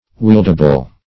Wieldable \Wield"a*ble\